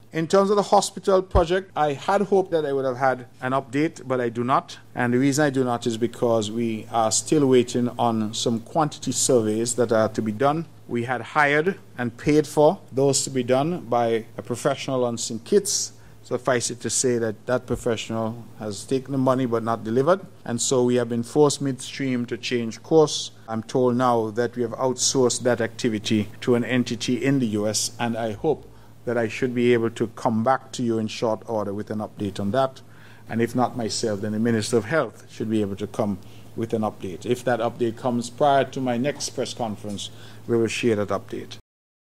That question was posed to the Premier of Nevis, the Hon. Mark Brantley, during his press conference on Aug. 29th. This was his response.